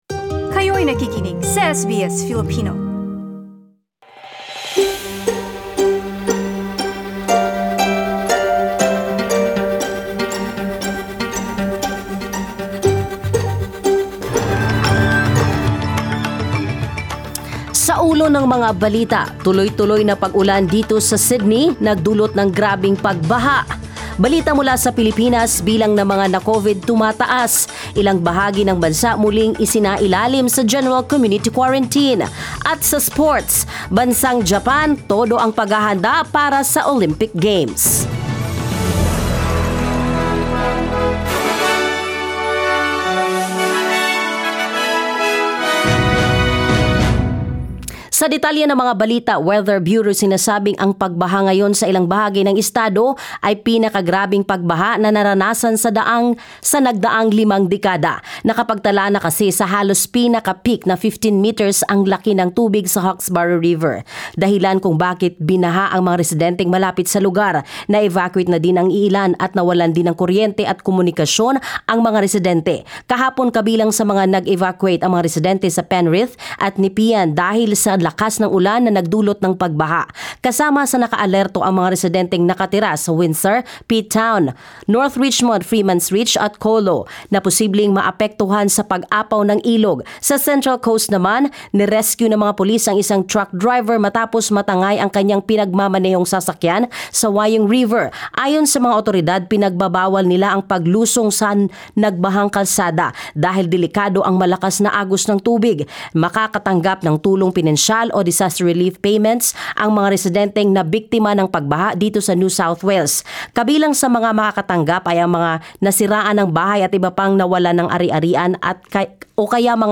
SBS News in Filipino, Monday 22 March